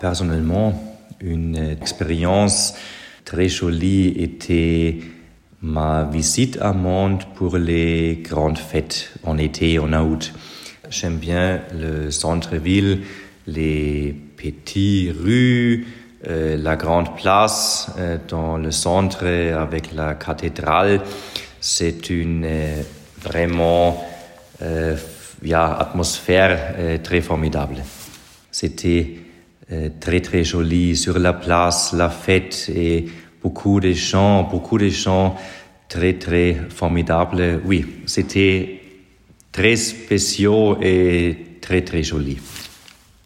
Nous remercions Nicolas Lahovnik pour ses réponses dans un français irréprochable.